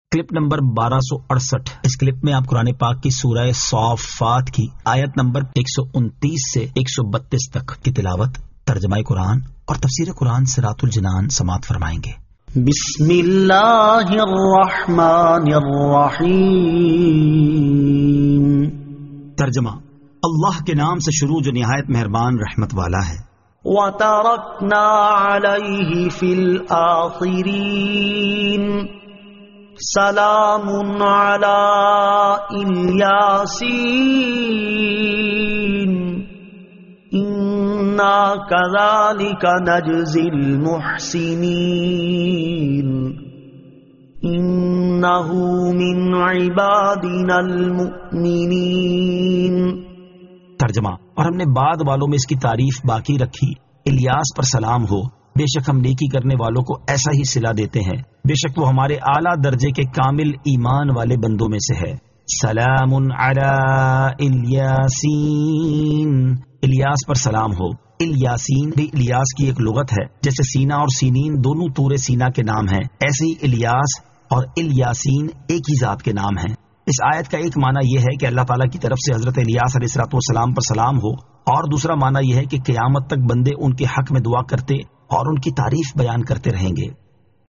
Surah As-Saaffat 129 To 132 Tilawat , Tarjama , Tafseer
2023 MP3 MP4 MP4 Share سُوَّرۃُ الصَّافَّات آیت 129 تا 132 تلاوت ، ترجمہ ، تفسیر ۔